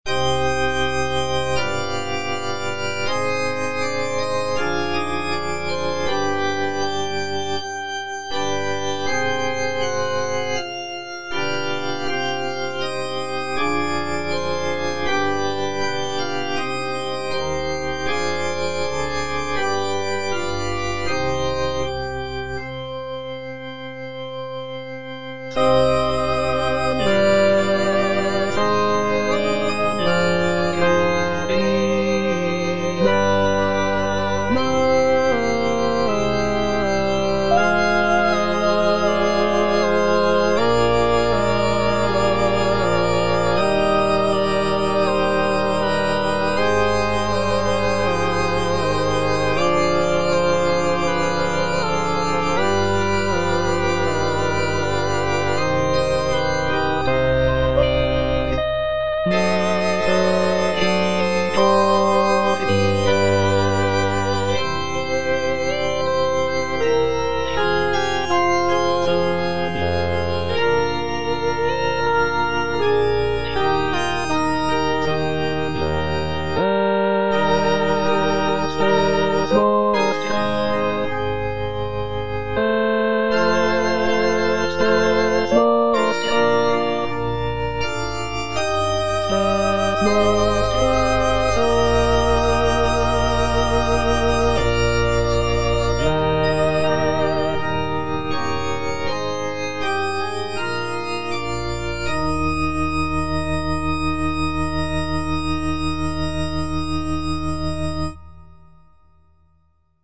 G.B. PERGOLESI - SALVE REGINA IN C MINOR Salve Regina (All voices) Ads stop: auto-stop
"Salve Regina in C minor" is a sacred choral work composed by Giovanni Battista Pergolesi in the early 18th century. It is a setting of the traditional Marian antiphon "Salve Regina" and is known for its poignant and expressive melodies. The piece is scored for soprano soloist, string orchestra, and continuo, and showcases Pergolesi's skill in writing for voice and orchestra.